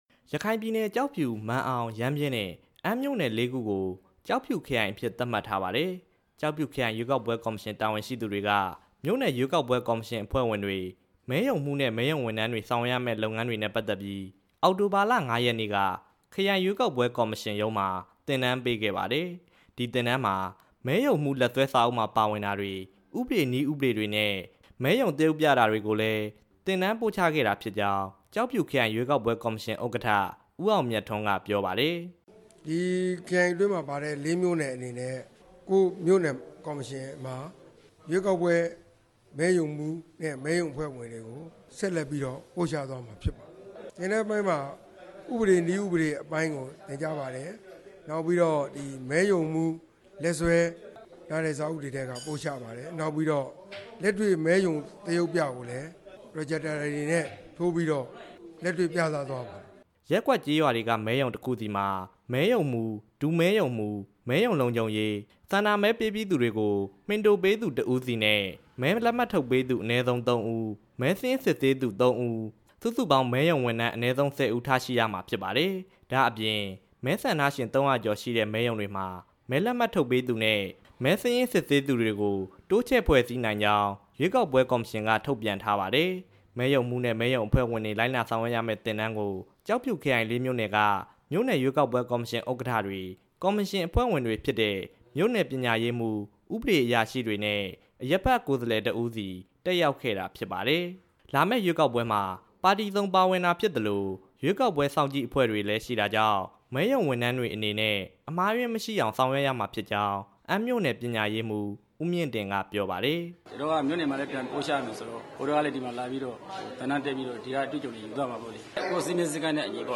ကျောက်ဖြူမြို့ကနေ တင်ပြထားပါတယ်။